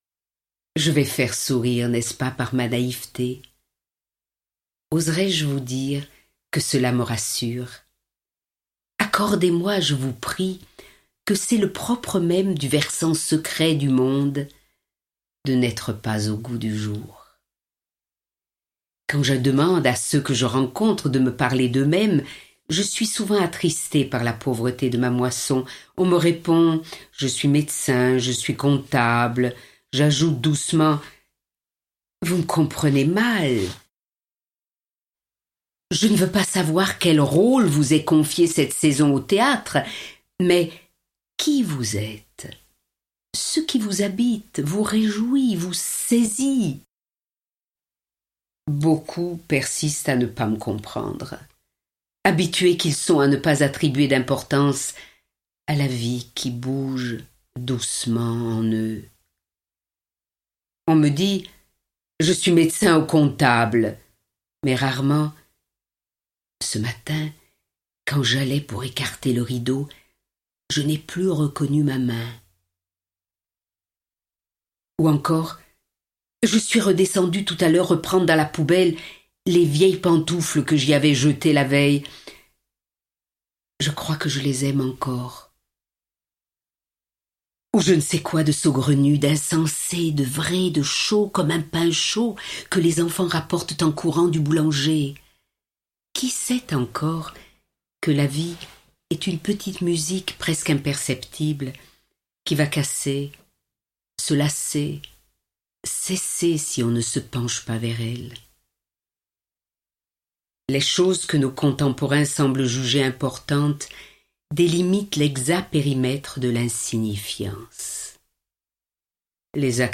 Toute la richesse de son humanité passionnée y est rassemblée, et, bonheur en plus, elle la lit à haute voix…